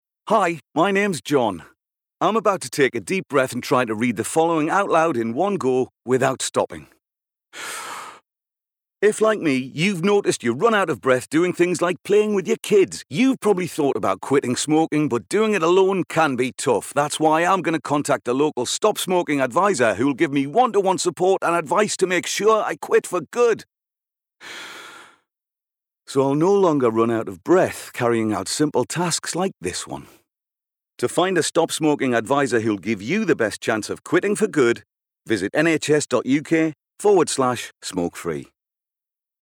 Friendly, conversational with gravitas.
• Male
• Newcastle (Geordie)